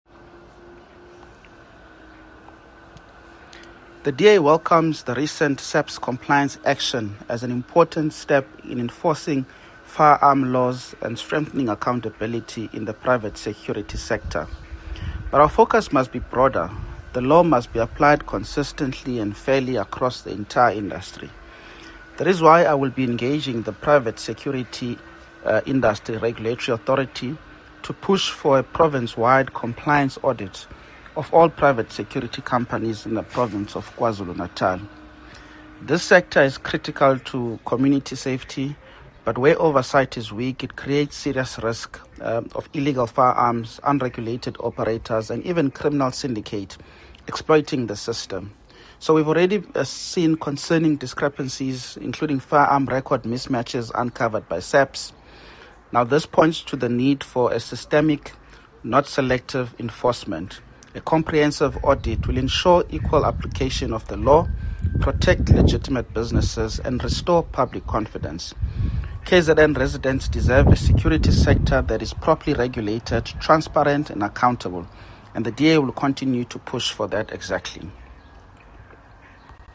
soundbite by Mzamo Billy MP.